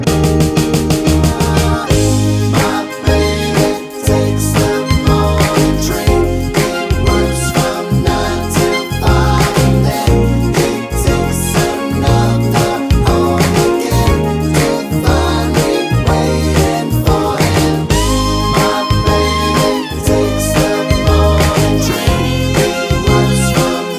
no Backing Vocals Soundtracks 3:24 Buy £1.50